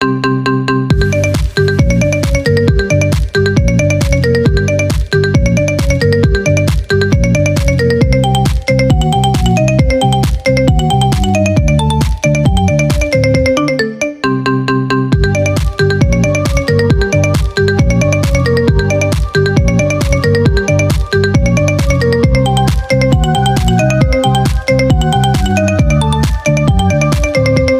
Kategorien: Marimba Remix